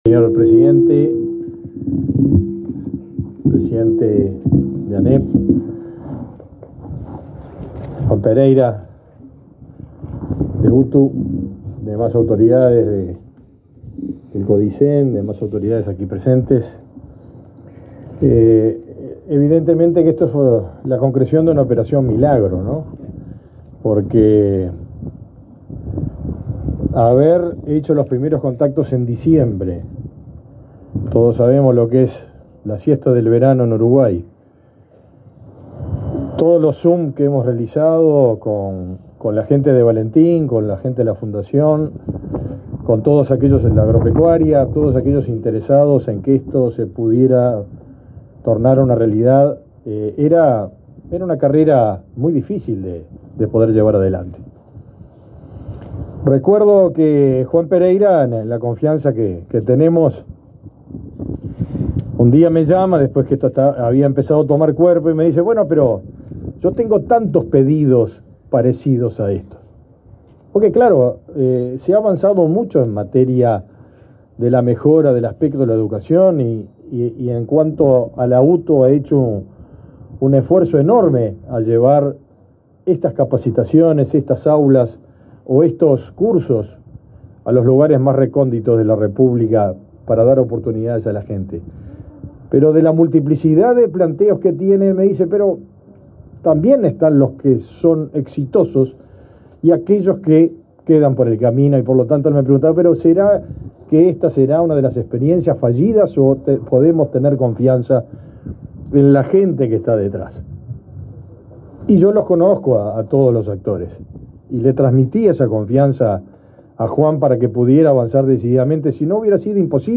Palabras del ministro de Ganadería, Agricultura y Pesca, Fernando Mattos
Palabras del ministro de Ganadería, Agricultura y Pesca, Fernando Mattos 24/06/2024 Compartir Facebook X Copiar enlace WhatsApp LinkedIn Este 24 de junio, la Dirección General de Educación Técnico Profesional (DGETP-UTU) inauguró el anexo de la escuela agraria de Rincón de Valentín, en el departamento de Salto. En el evento disertó el ministro de Ganadería, Agricultura y Pesca, Fernando Mattos.